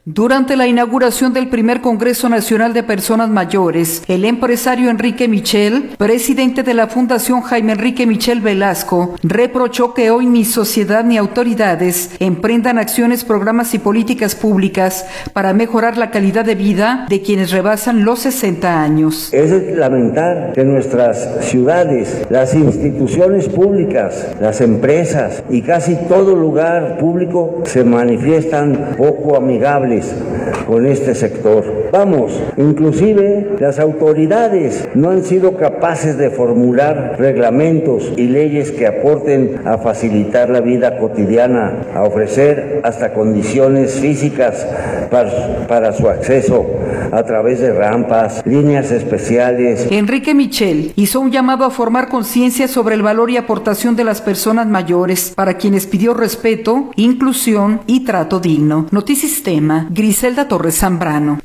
Durante la inauguración del Primer Congreso Nacional de Personas Mayores